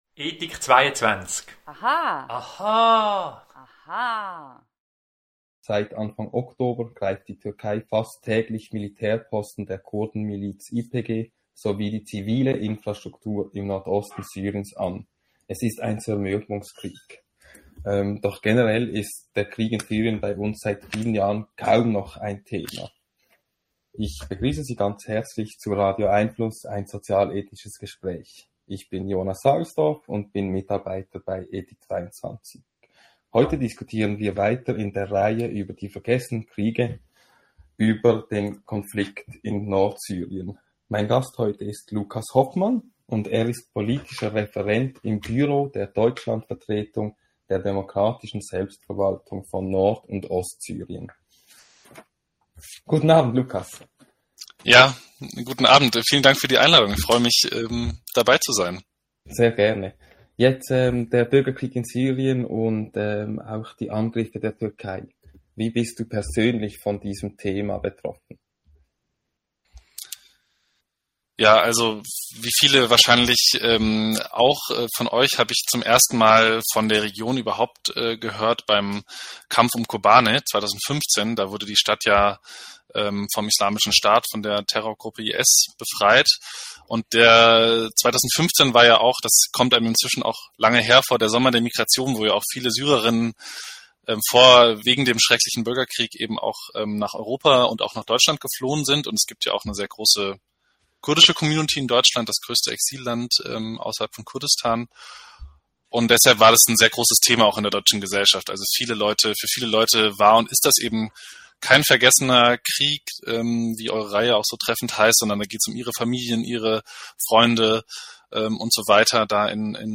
Podcast hier zuhören Radio🎙einFluss findet jeden Mittwoch 18:30 - 19 Uhr statt .